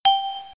ding.wav